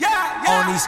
Ya Ya 2.wav